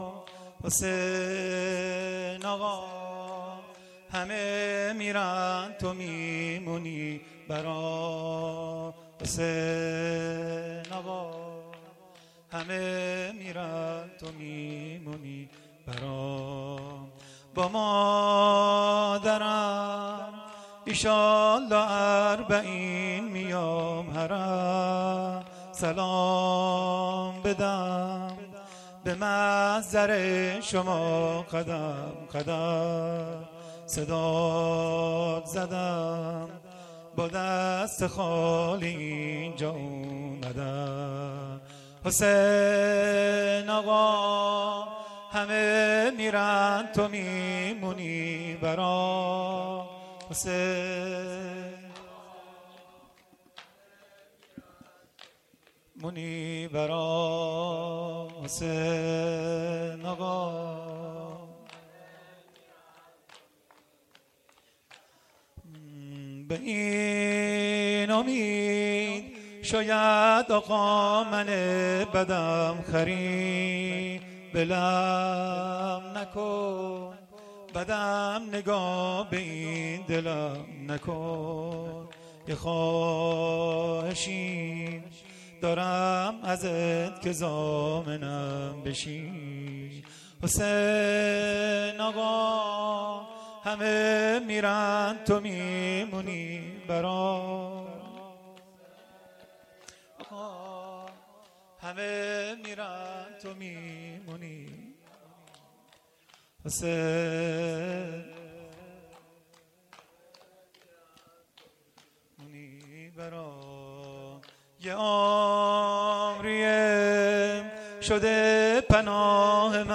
شور _ حسین آقام _ شب چهارم محرم الحرام ۱۴۴۱